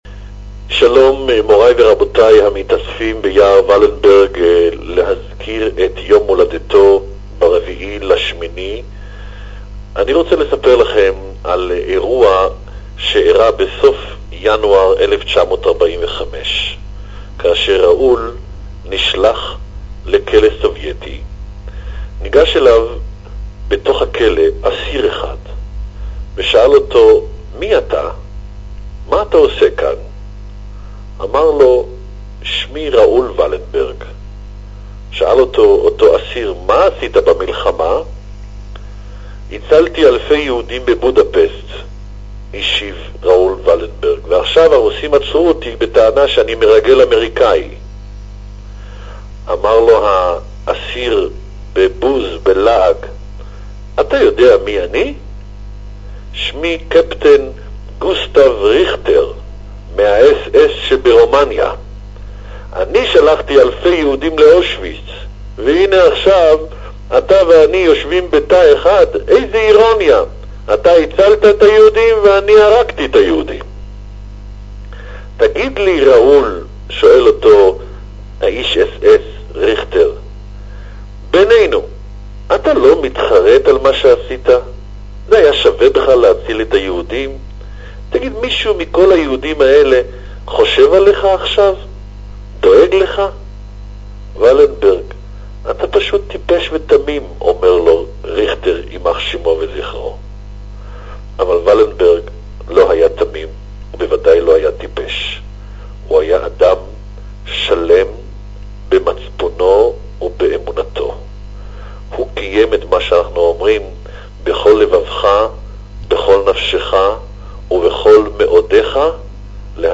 Recorded Message by Israel's Chief Rabbi
Ashkenazi Chief Rabbi Yona Metzger made a taped statement, because he could not be in Jerusalem for the event. He described an ironic encounter in a Soviet jail between Raoul Wallenberg the rescuer of Jews and a Nazi who sent many Jews to their death.